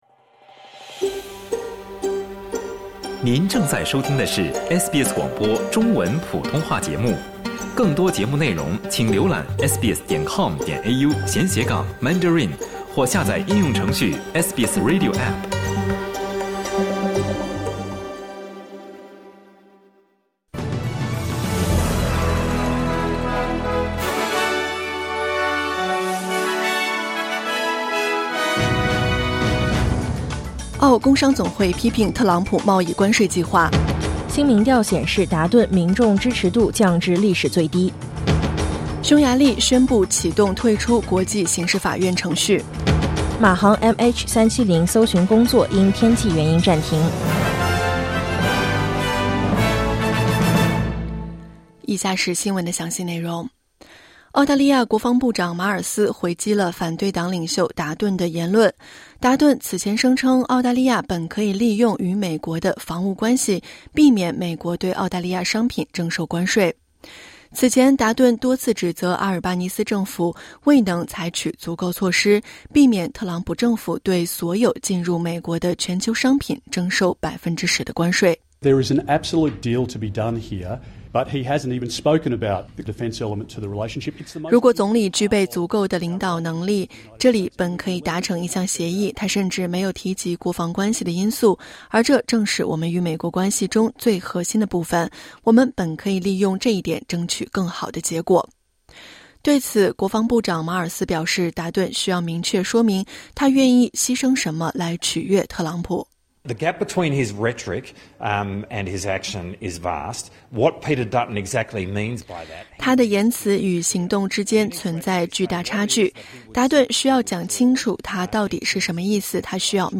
SBS早新闻（2025年4月4日）